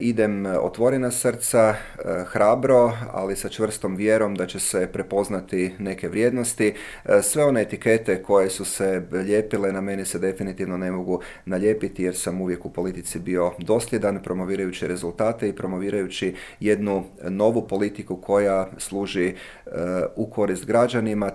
ZAGREB - Međimurski župan Matija Posavec, nositelj HNS-ove liste za europske izbore, u razgovoru za Media servis otkrio je zašto ustraje na samostalnom izlasku, predstavlja li HNS-u vodstvo uteg, planira li zasjesti na mjesto predsjednika stranke i zašto nije bio za ulazak u vladajuću koaliciju.